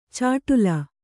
♪ cāṭula